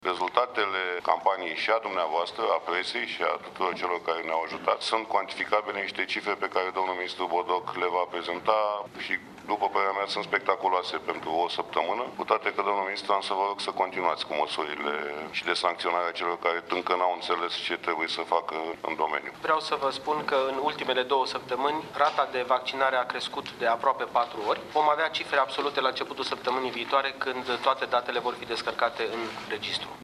Ministrul Sănătății, Florian Bodog, a spus, la începutul sedinței de guvern, că, în ultimele două săptămâni, rata de vaccinare a crescut de aproape 4 ori, dar datele exacte vor fi anunțate abia săptămâna viitoare.